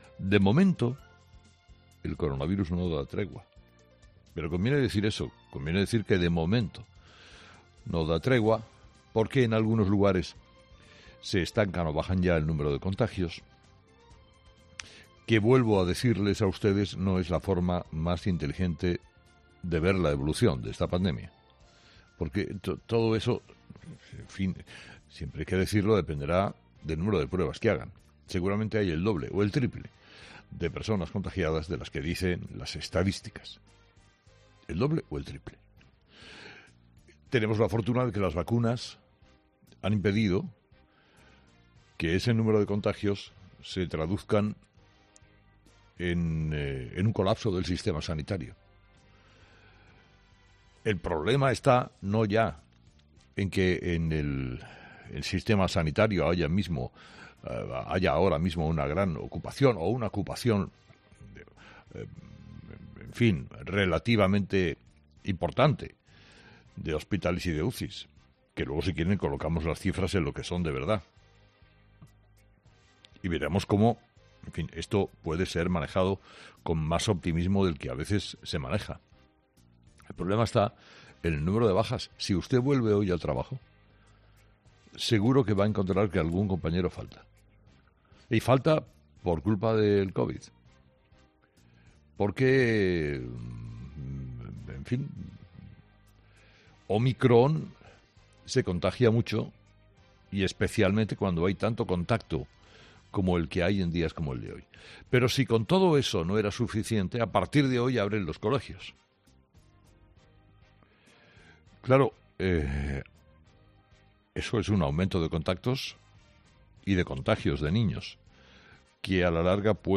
Carlos Herrera, director y presentador de 'Herrera en COPE', comenzaba el programa analizando las principales claves de la jornada, que pasaban, entre otros asuntos, por el alza de contagios que sigue registrando la sexta ola en nuestro país: "El coronavirus no da tregua.